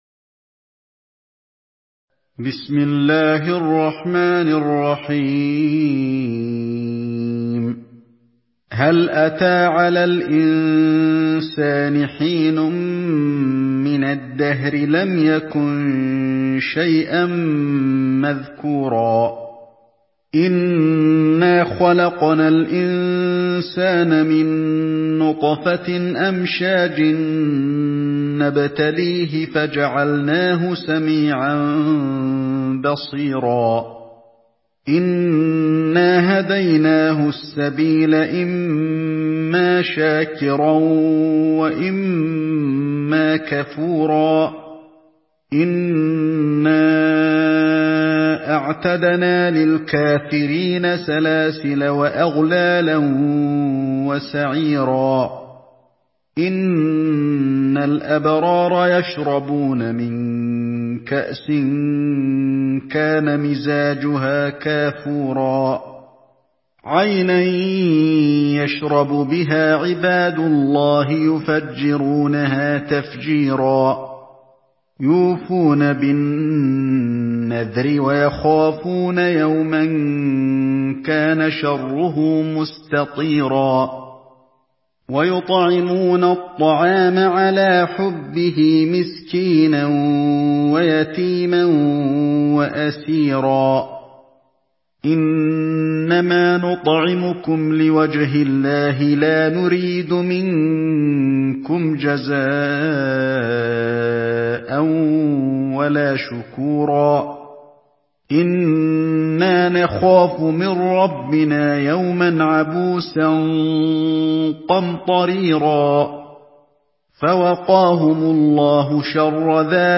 Surah الإنسان MP3 by علي الحذيفي in حفص عن عاصم narration.